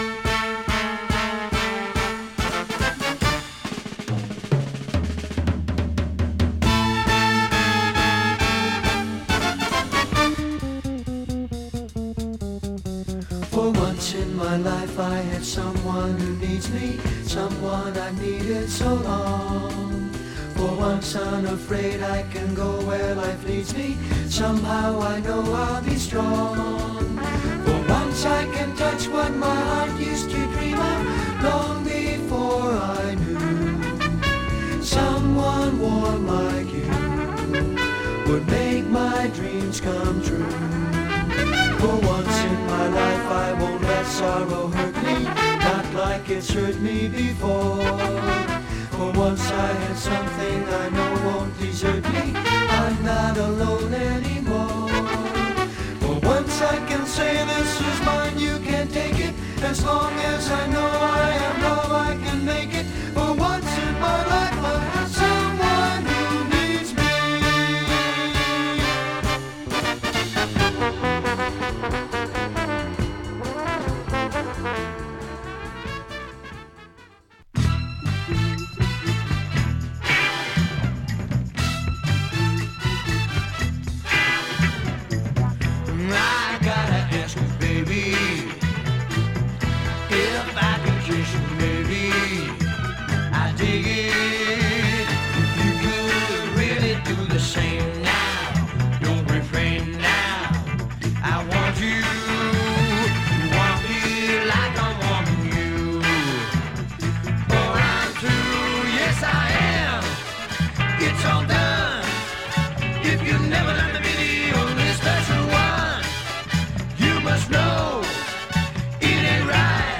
米 ウィスコンシン周辺で活動していたソフトロック系グループ。